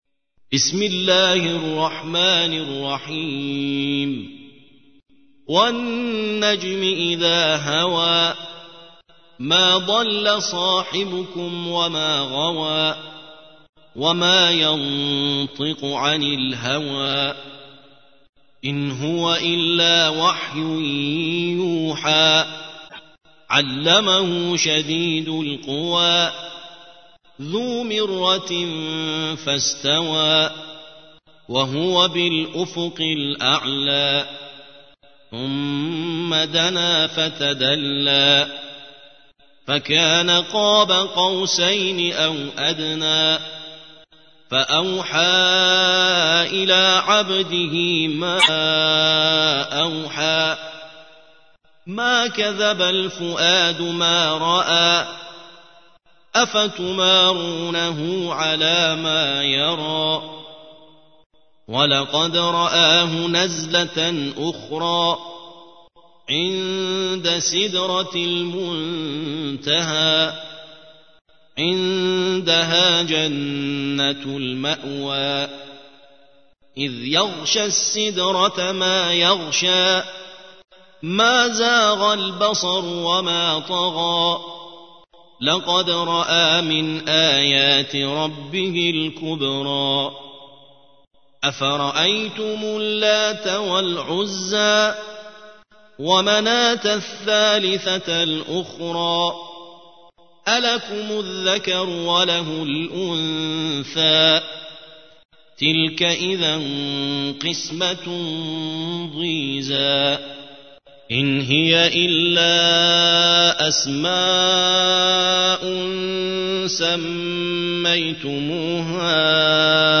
53. سورة النجم / القارئ